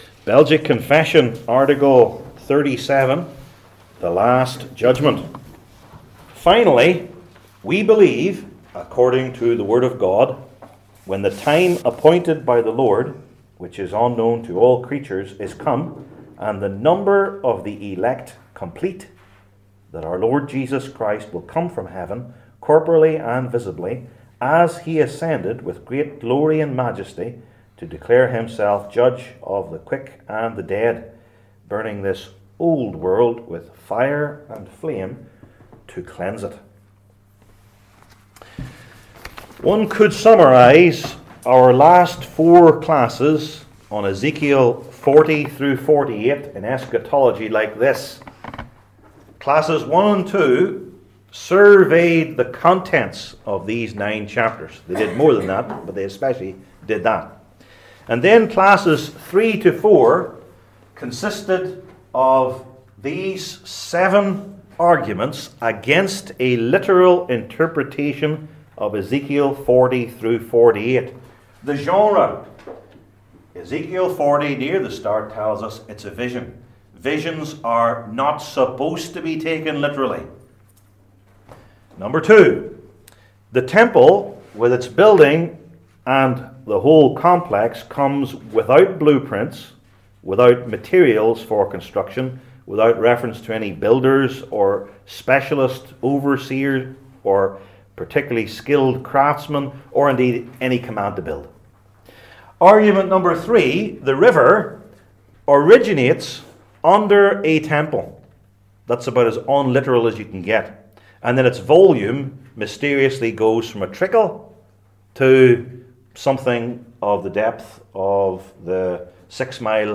Passage: Ezekiel 45:18-25 Service Type: Belgic Confession Classes